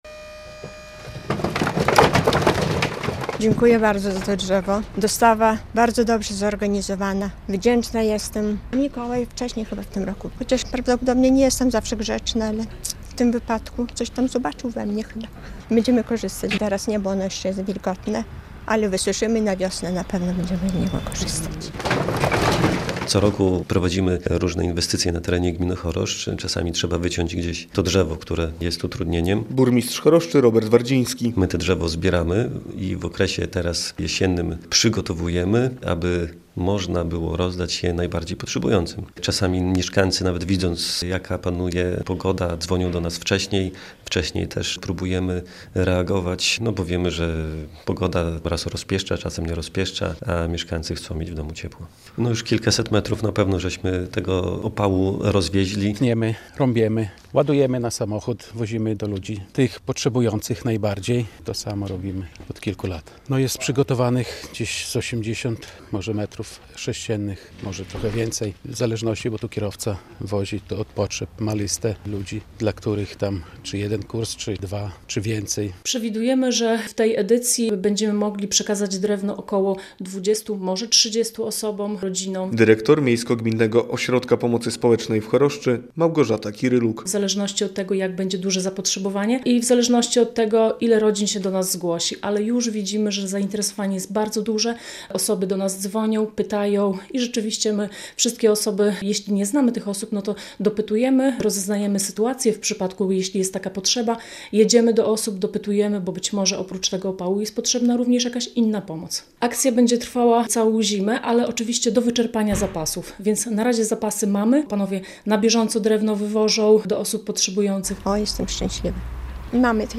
Im więcej inwestycji, tym więcej opału w danym roku możemy przekazać. Wiadomo, że robiąc coś, czasami trzeba wyciąć jakieś przeszkadzające drzewa. My to wszystko zbieramy i w okresie jesiennym przygotowujemy, tak by później gotowe drewno można było rozdać potrzebującym - mówi burmistrz Choroszczy Robert Wardziński.